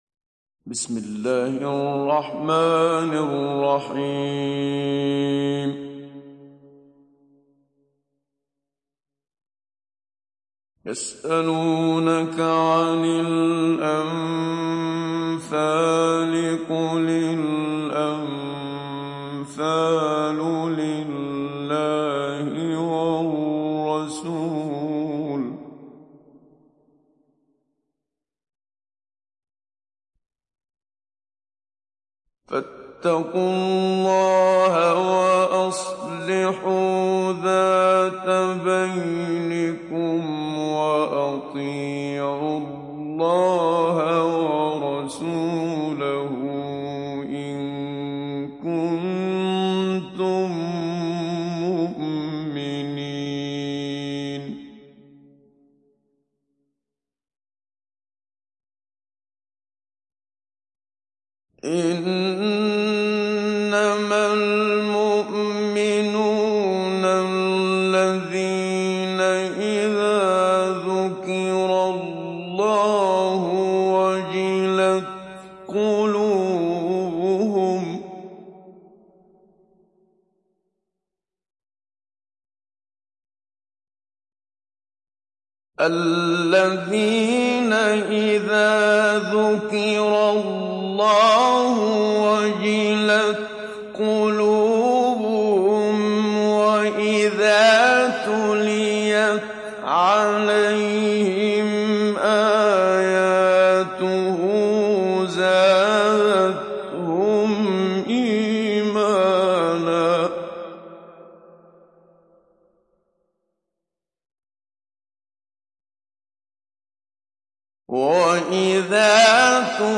ডাউনলোড সূরা আল-আনফাল Muhammad Siddiq Minshawi Mujawwad